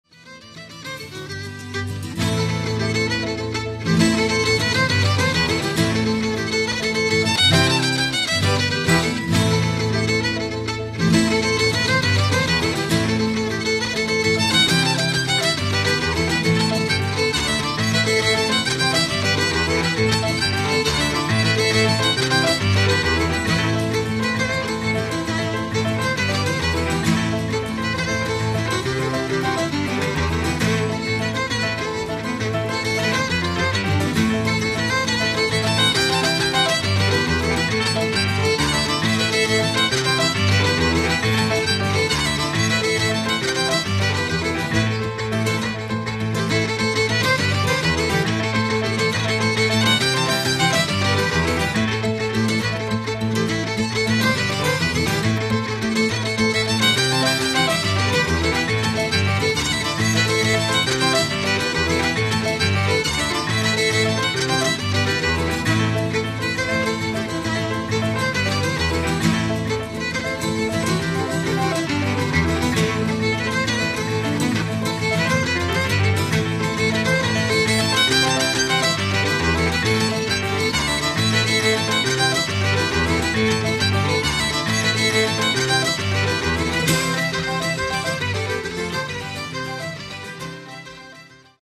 A popular jig